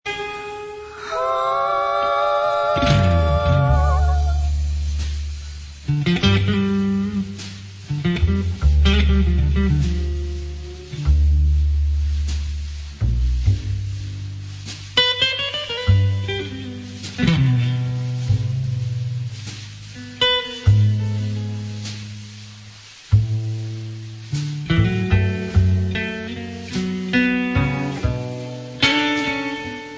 Jazz